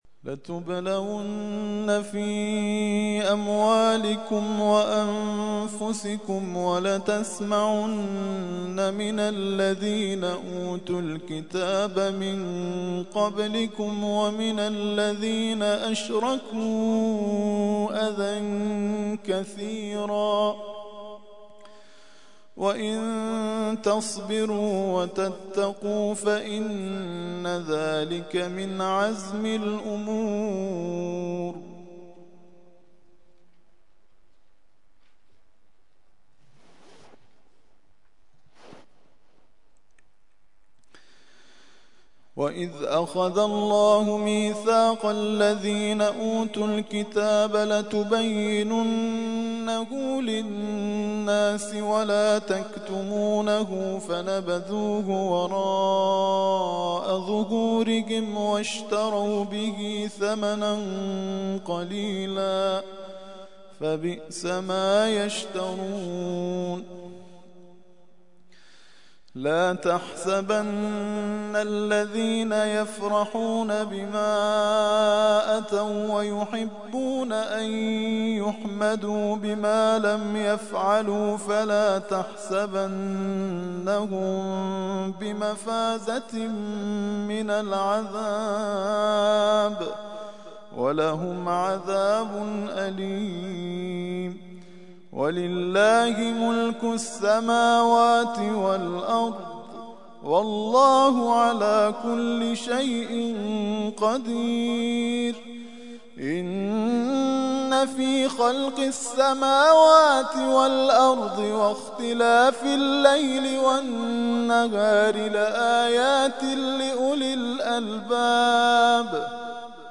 ترتیل خوانی جزء ۴ قرآن کریم در سال ۱۳۹۱